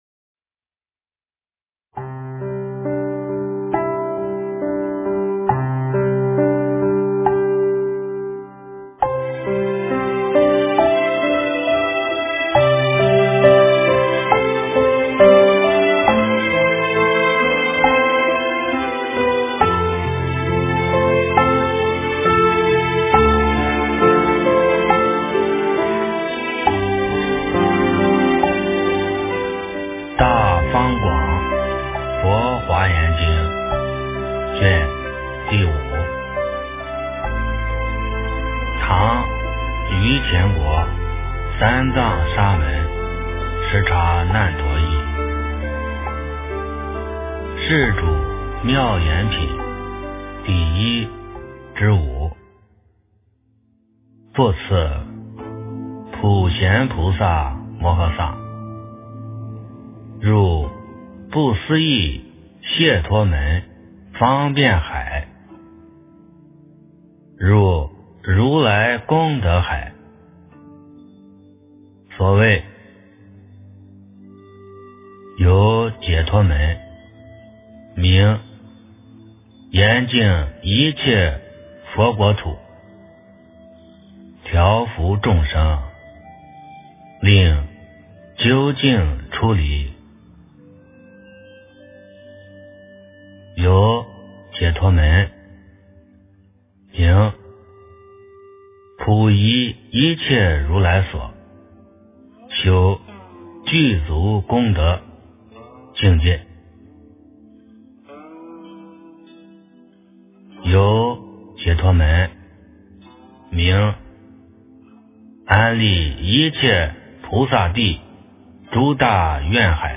《华严经》05卷 - 诵经 - 云佛论坛